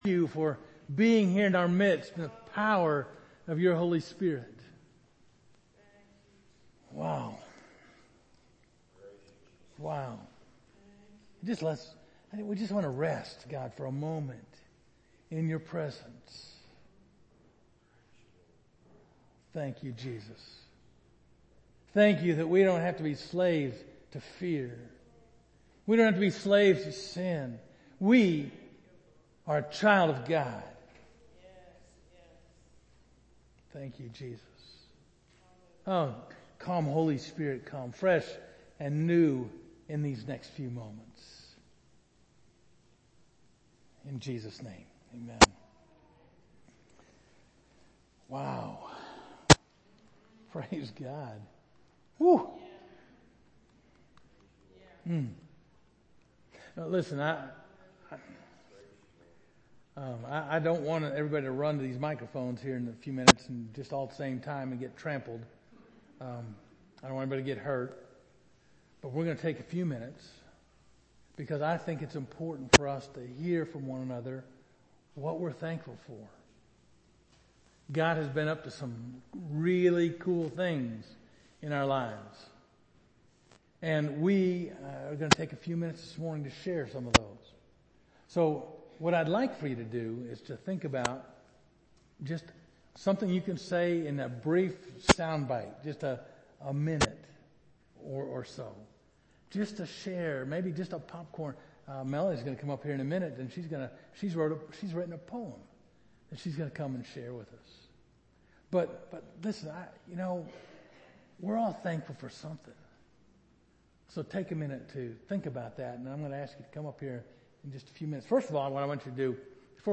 Covenant United Methodist Church Sermons